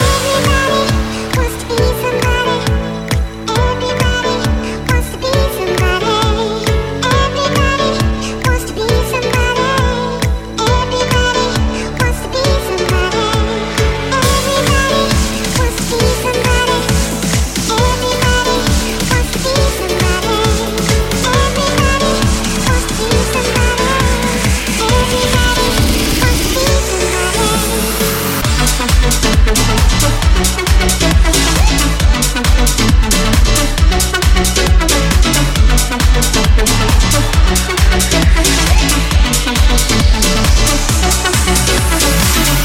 hit - nuove proposte - remix
Genere: pop, club, deep, remix